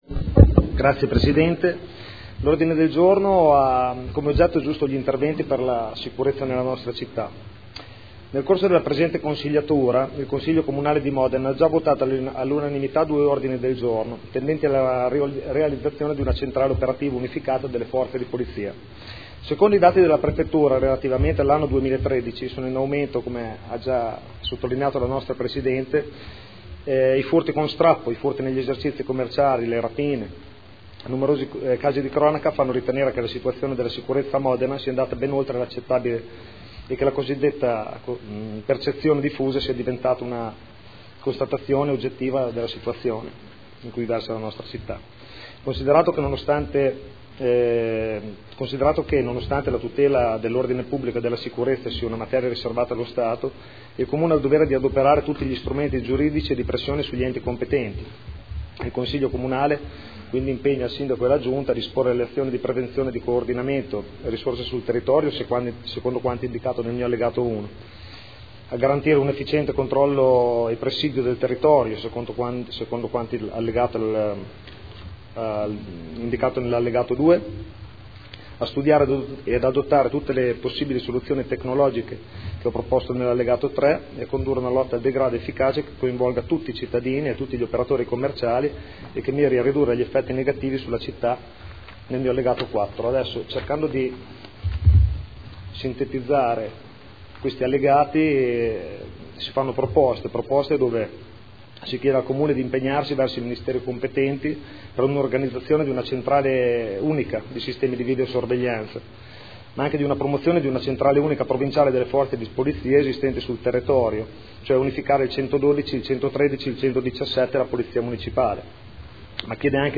Fabrizio Cavani — Sito Audio Consiglio Comunale